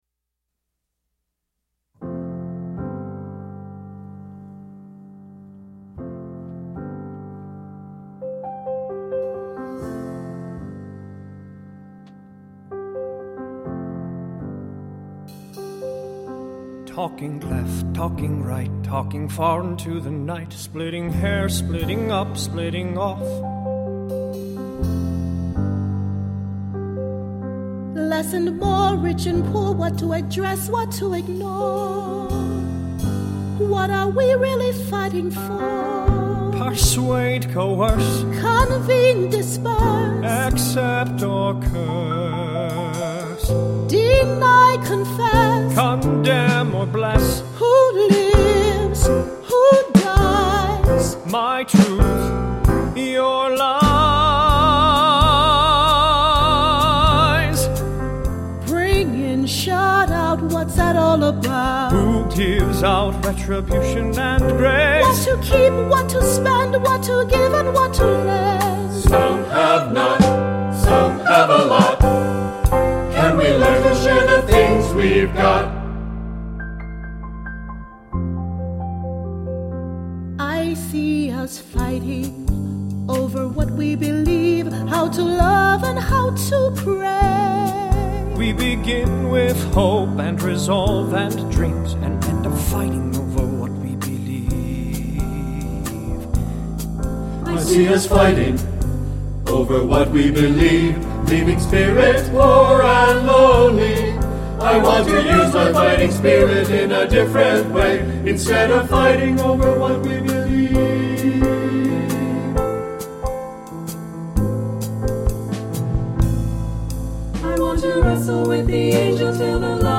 SATB, soloists, piano